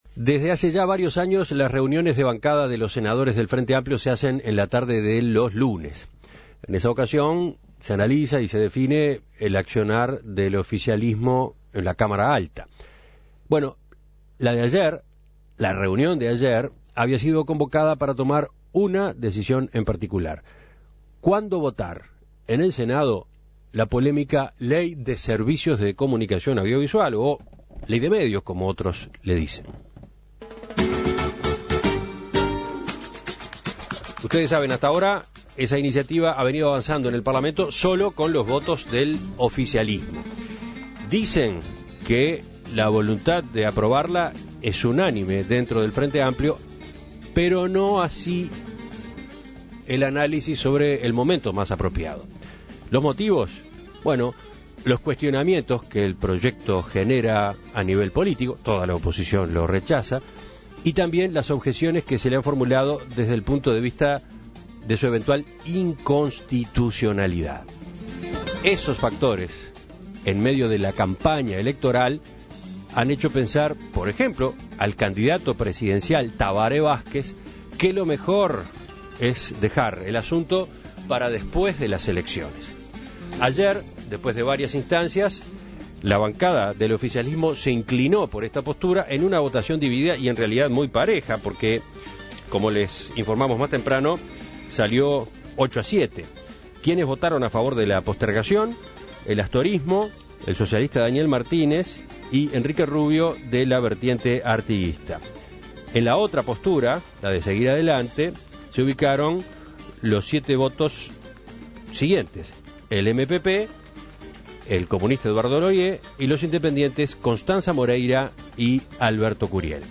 En Perspectiva entrevistó al senador por el MPP Ernesto Agazzi acerca de esta postergación y sus implicancias para el oficialismo.